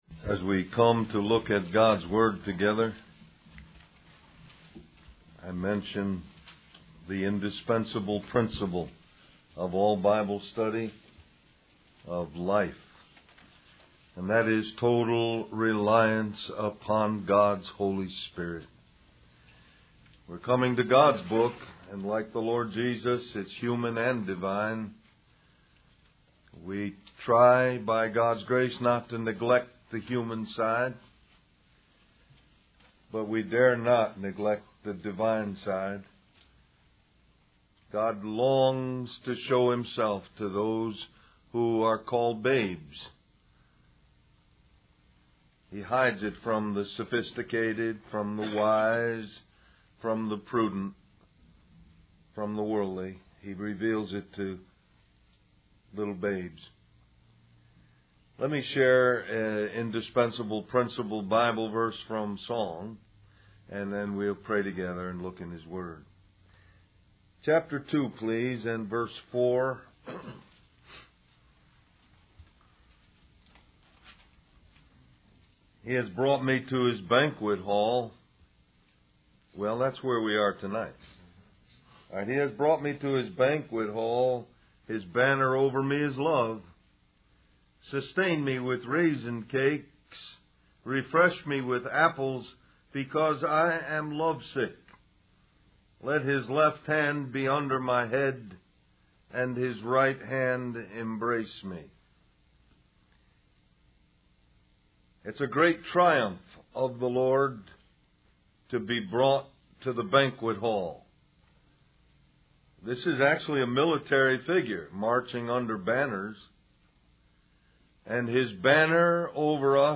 Del-Mar-Va Men's Retreat